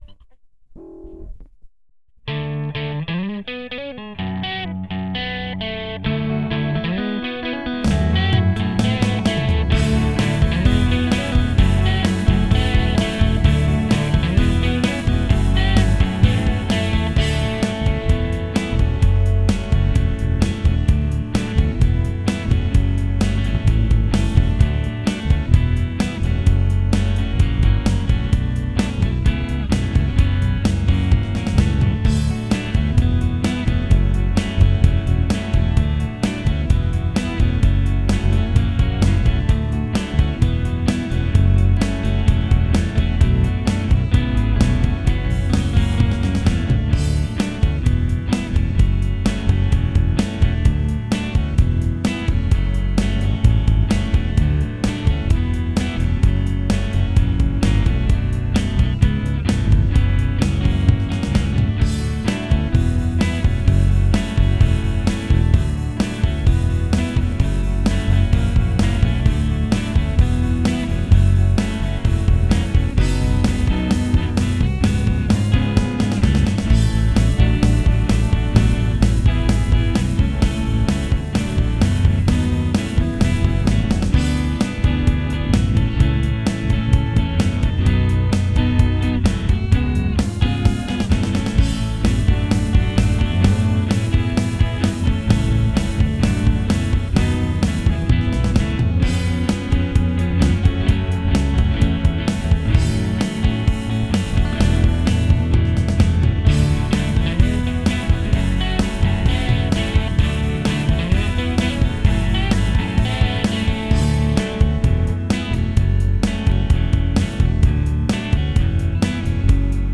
TR1　Drams（MIDIデーター）
TR2　E-Giter
TR3　E-Giiter
TR6 E-PIANO(メロディ）
印象的なイントロからアルペジオ、ドラムとベースで盛り上がっていく感じがいいです。